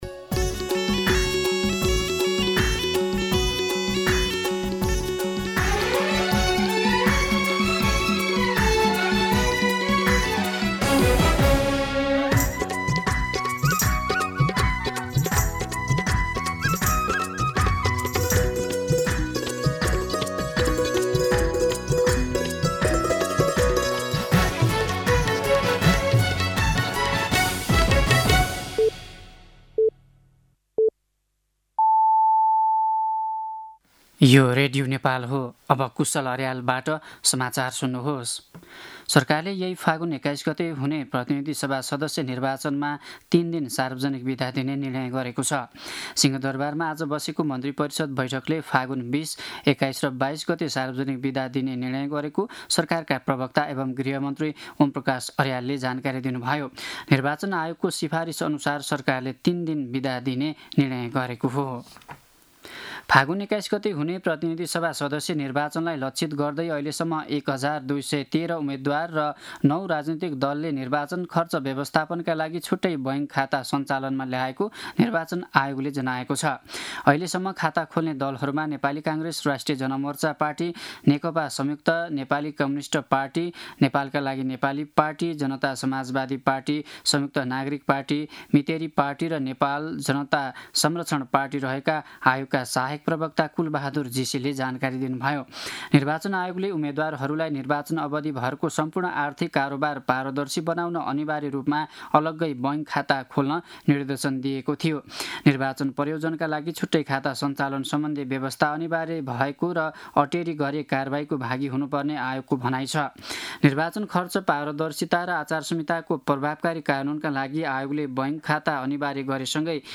दिउँसो ४ बजेको नेपाली समाचार : १२ फागुन , २०८२
4pm-News-11-12.mp3